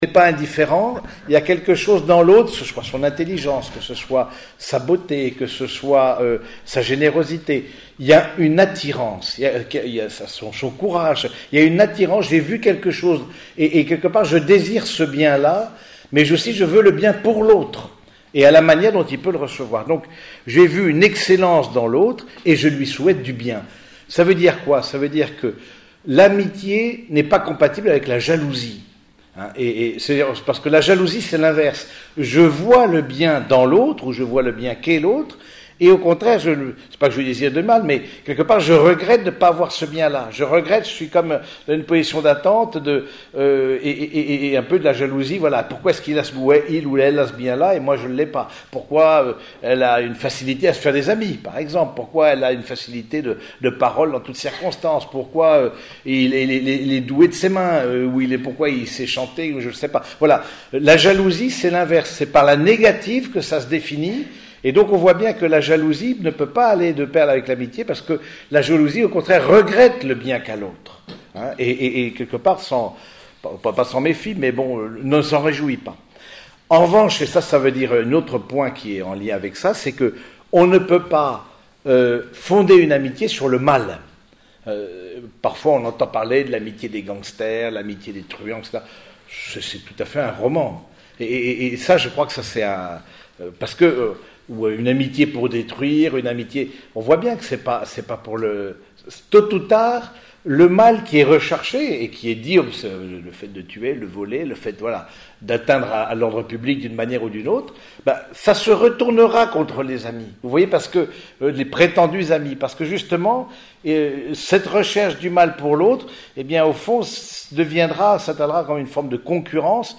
Conference_sur_l_amitie.MP3